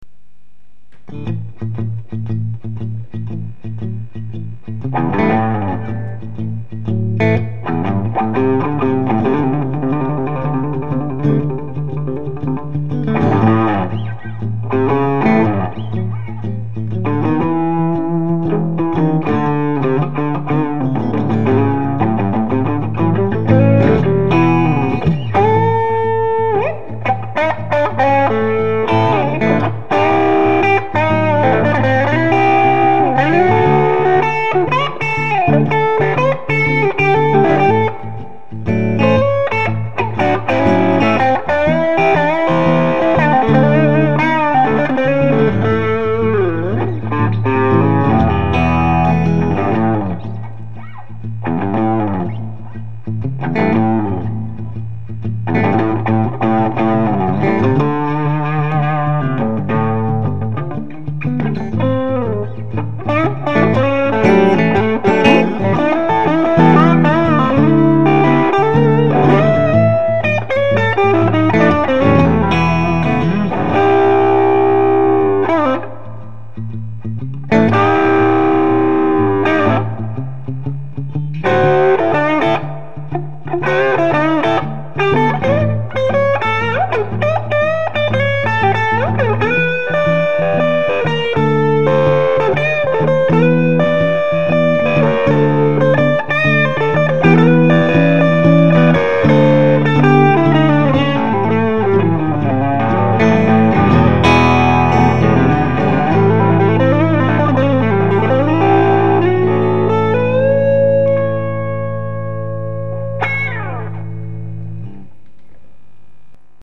mine de rien j'ai entendu des samples d'un mec qui a acheté une telecaster chez Ishibashi je vous file les liens vers les samples ca arrache tout